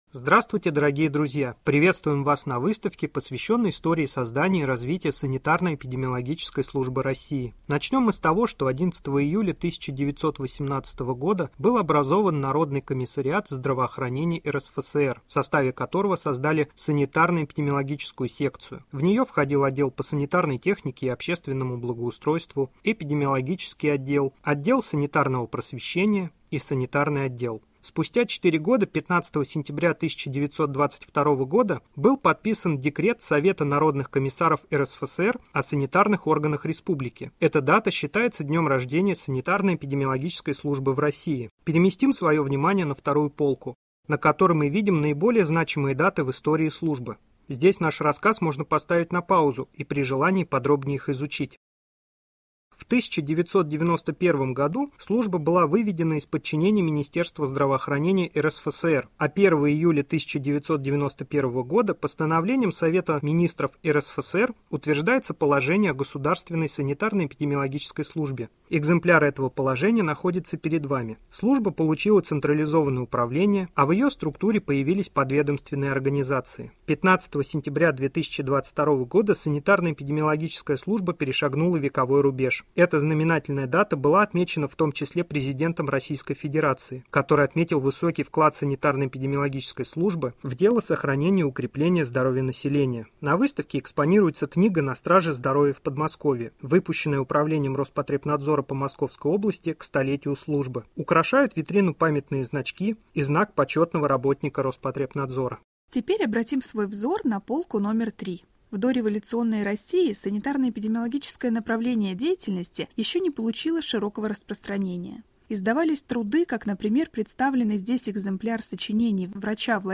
Аудиоэкскурсия.mp3